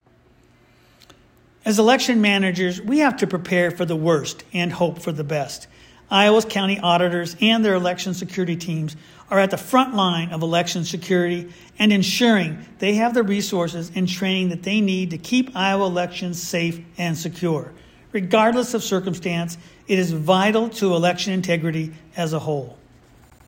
A soundbyte from Secretary Pate is available for your use at this link.